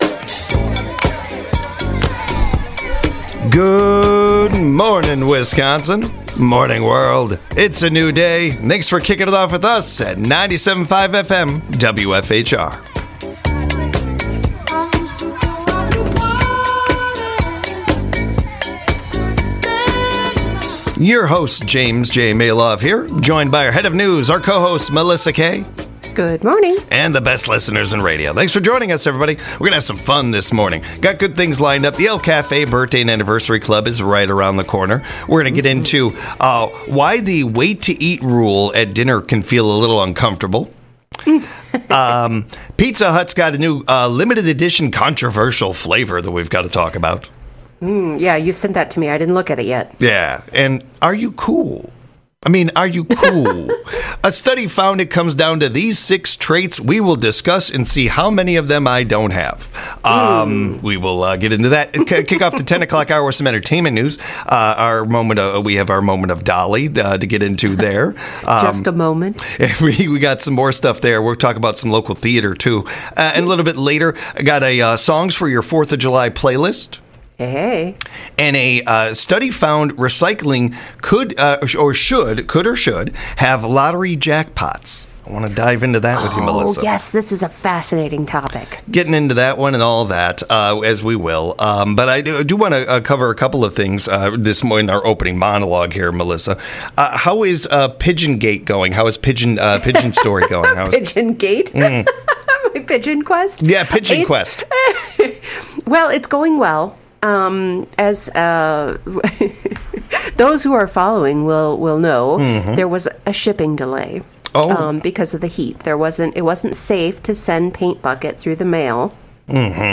They wish everyone a happy birthday and anniversary with the El Café Birthday and Anniversary Club! They have a conversation about politeness at restaurants .